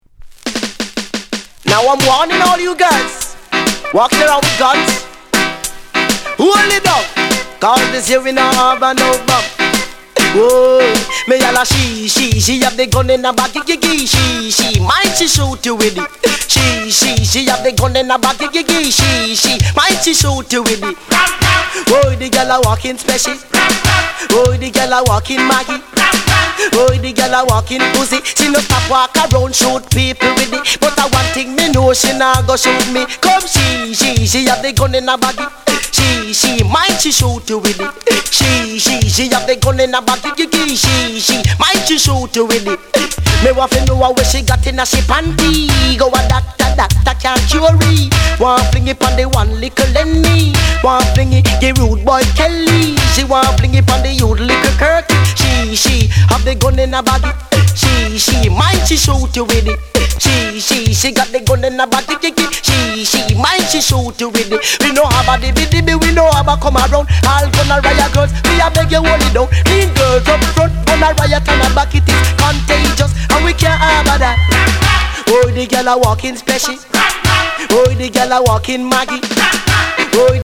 Genre: Dancehall